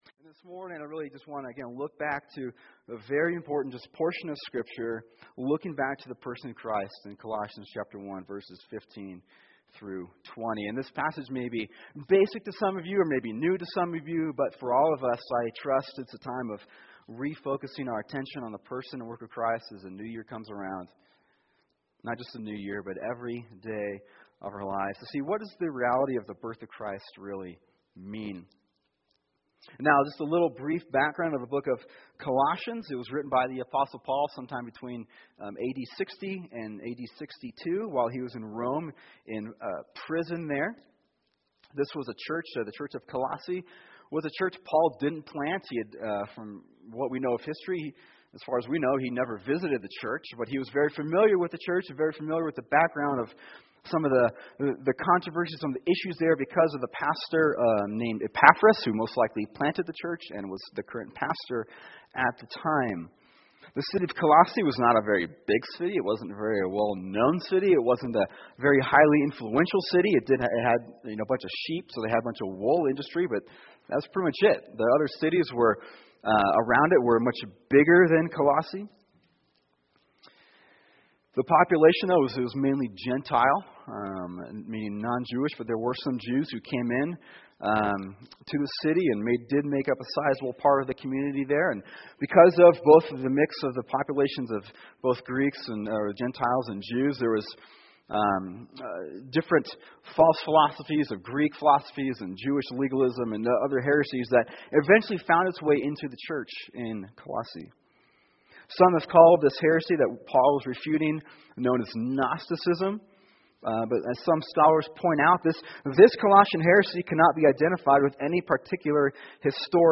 [sermon] Colossians 1:15-20 “The Child Who Was God” | Cornerstone Church - Jackson Hole